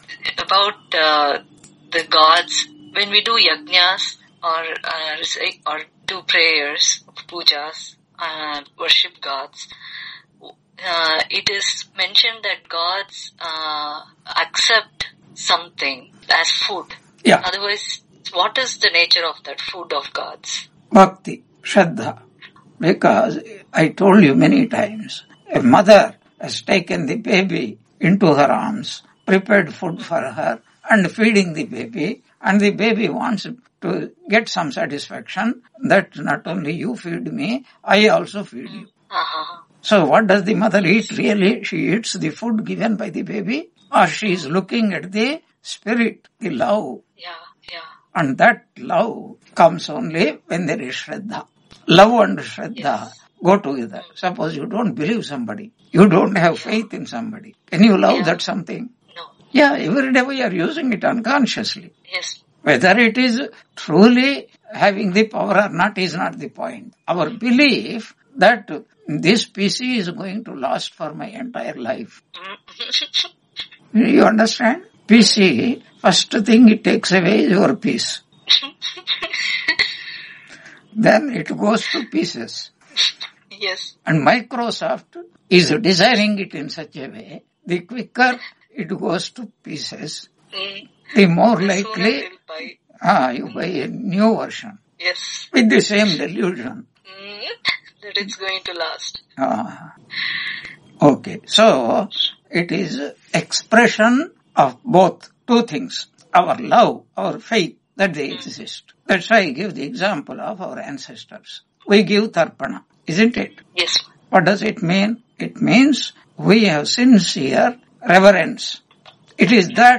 Brihadaranyaka Upanishad Ch.1.3 Lecture 15 on 14 March 2026 Q&A - Wiki Vedanta